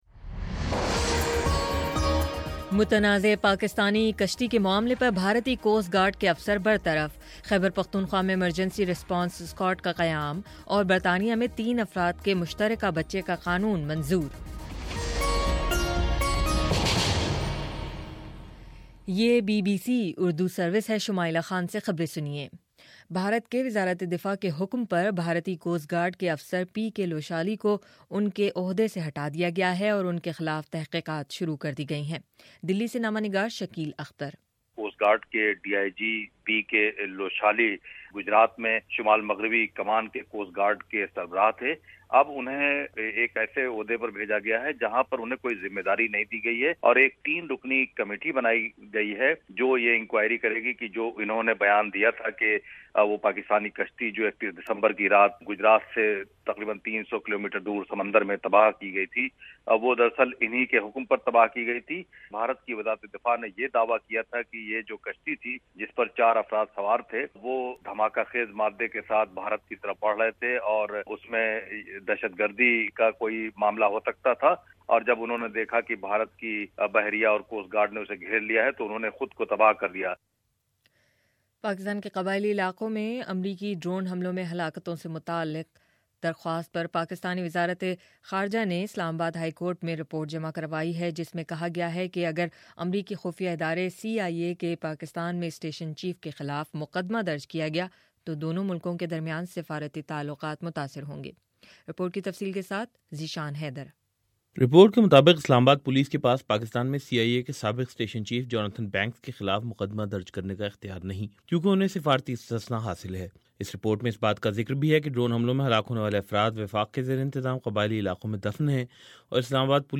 فروری 25 : صبح نو بجے کا نیوز بُلیٹن